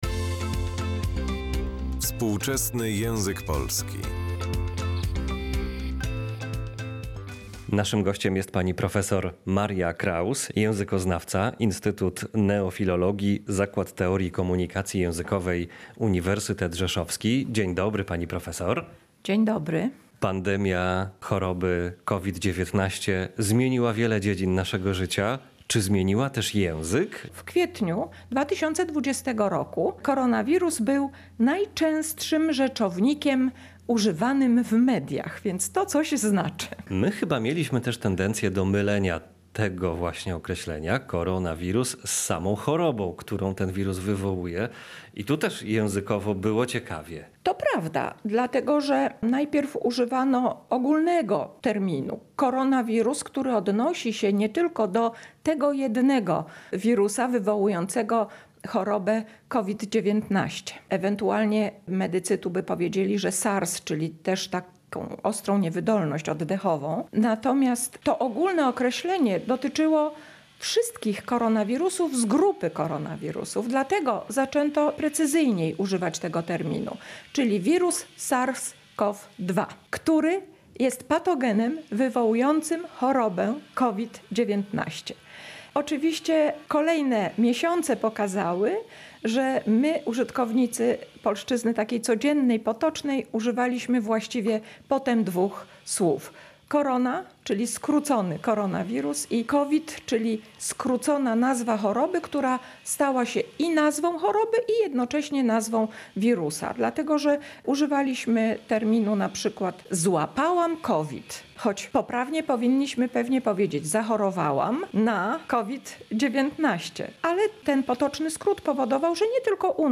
Posłuchaj rozmowy z ekspertem